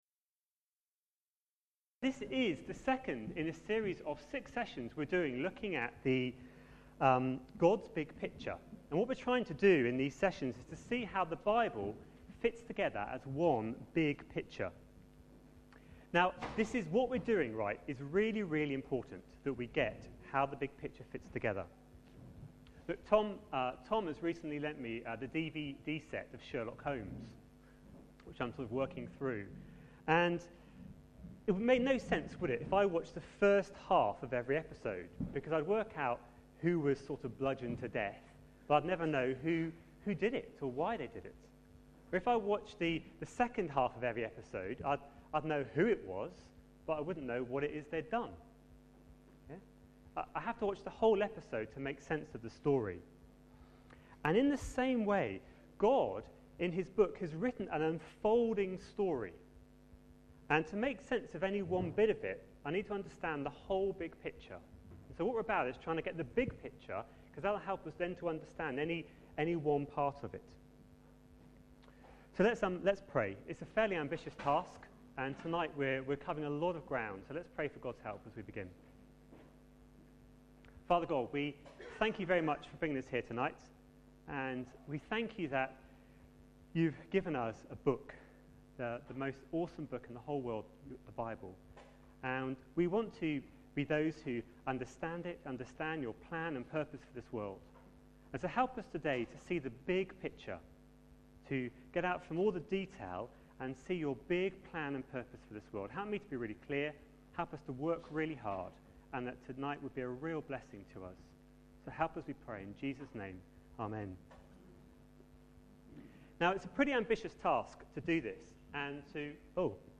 A sermon preached on 3rd October, 2010, as part of our God's Big Picture series.